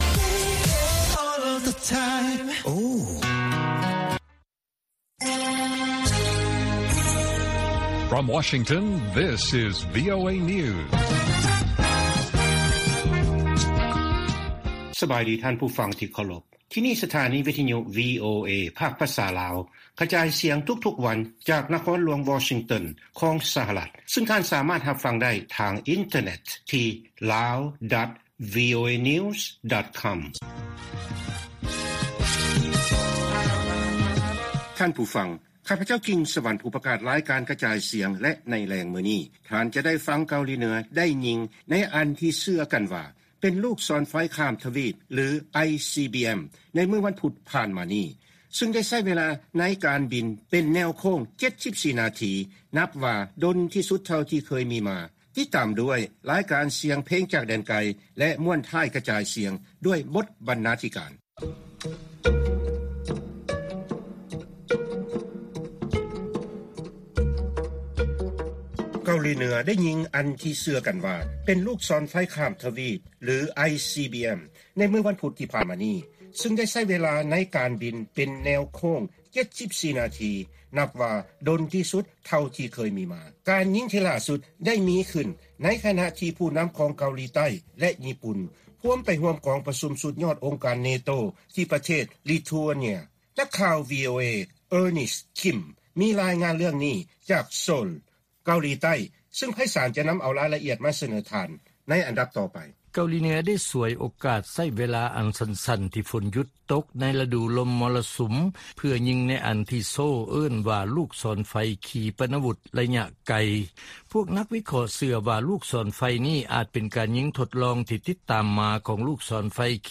ລາຍການກະຈາຍສຽງຂອງວີໂອເອ ລາວ: ເກົາຫຼີເໜືອຍິງລູກສອນໄຟຂ້າມທະວີບ ຫຼື ICBM ເຊິ່ງບິນເປັນເວລາດົນທີ່ສຸດ ເທົ່າທີ່ເຄີຍມີມາ